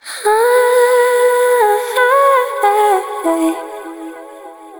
Soul Female House Vocal
soft-female-house-vocals-alley-souful-melody_E_major.wav